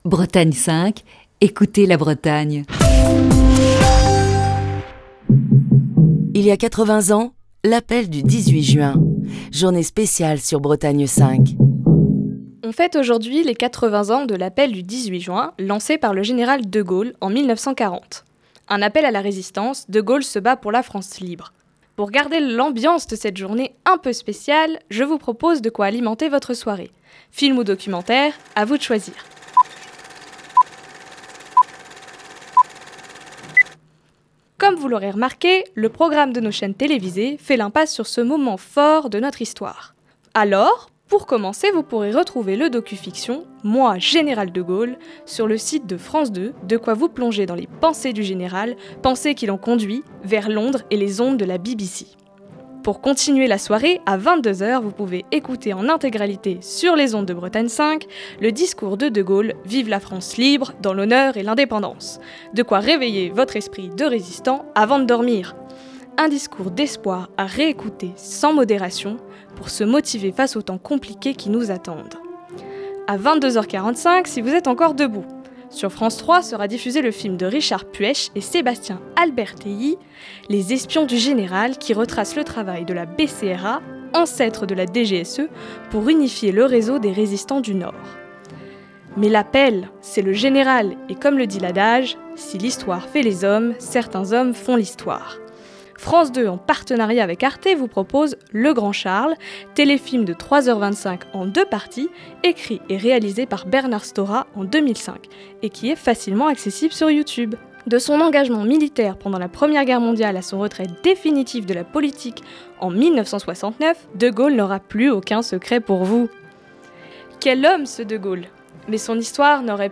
Chronique du 18 juin 2020. Journée spéciale 80 ans de l'appel du 18 juin.